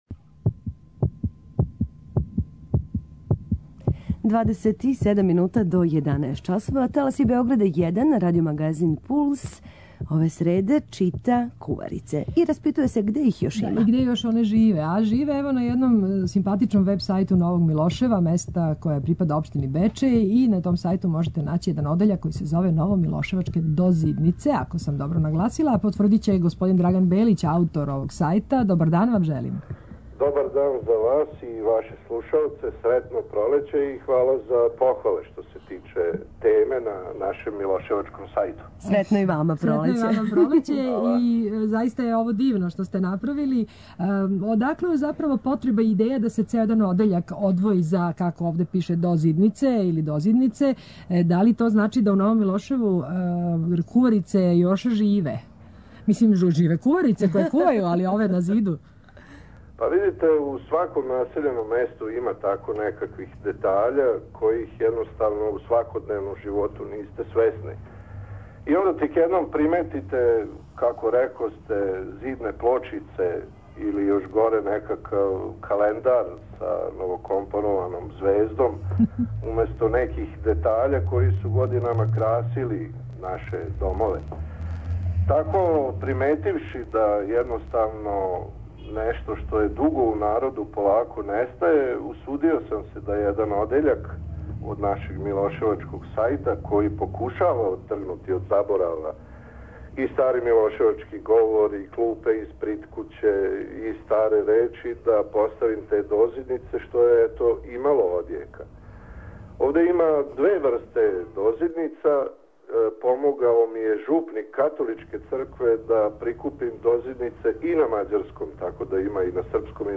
- Gostovali smo "uŽivo", a na "temu - doZidnica" ...